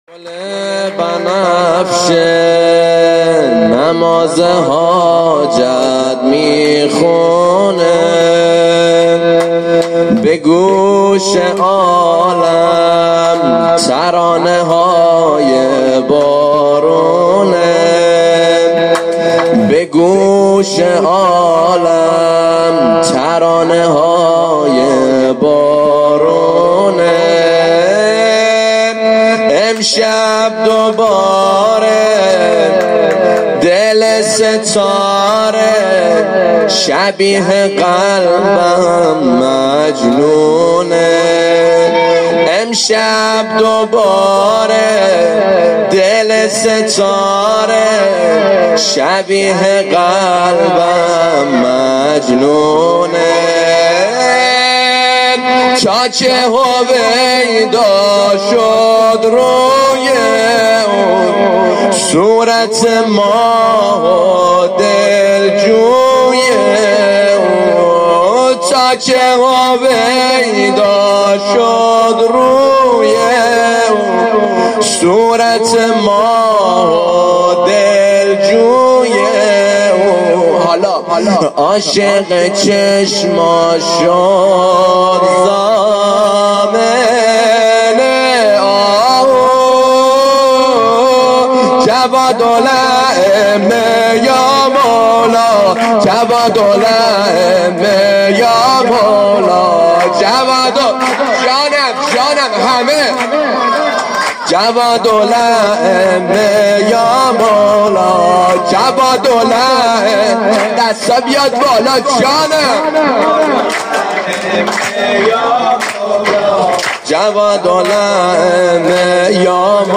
جشن ها